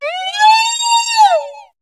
Cri de Cocotine dans Pokémon HOME.